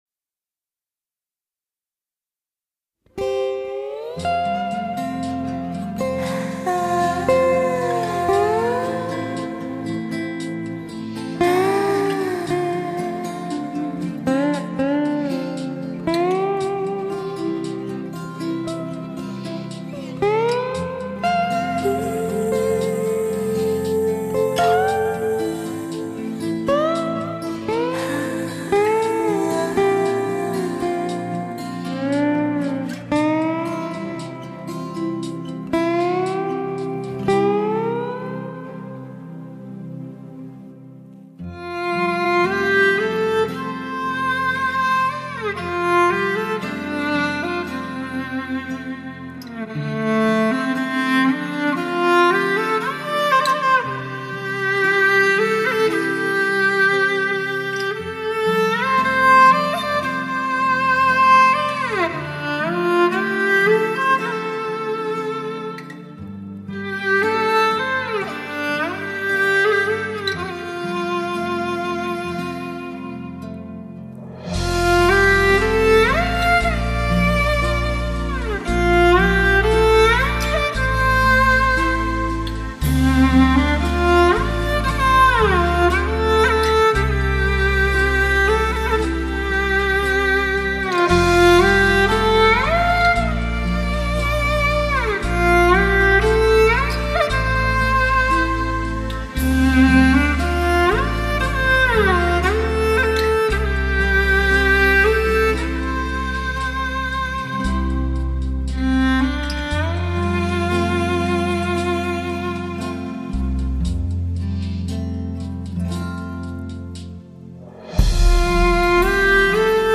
马头琴细腻如小提琴，醇厚如中提琴，深情如大提琴；
马头琴也如马语，深含悲悯之情，